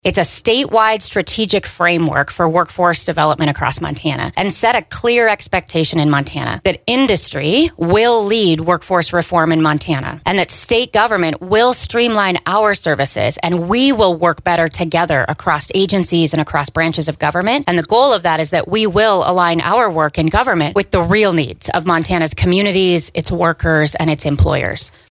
406 Jobs is a new initiative launched through an Executive Order by Governor Greg Gianforte earlier this week. Today on Voices of Montana Department of Labor and Industry Commissioner Sarah Swanson explained what it is and what it’ll do.